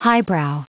Prononciation du mot highbrow en anglais (fichier audio)
Prononciation du mot : highbrow
highbrow.wav